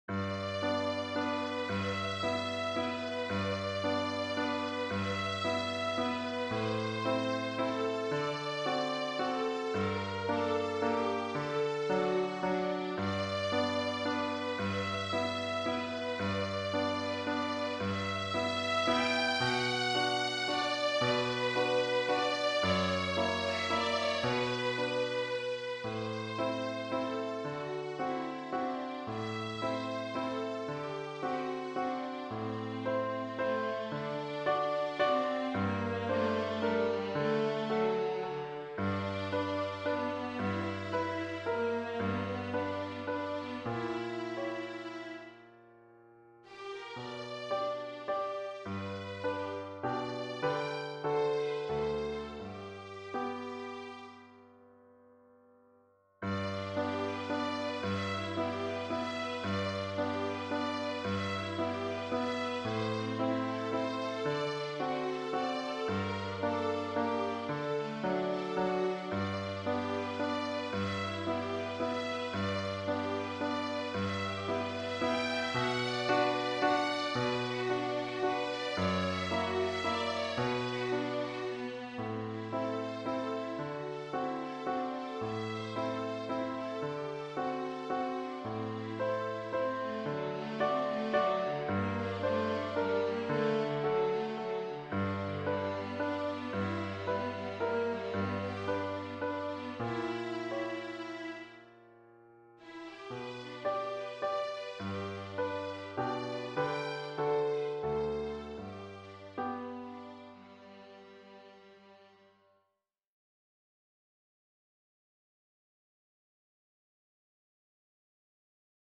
for viola and piano
A charming waltz with a variation in eighth notes.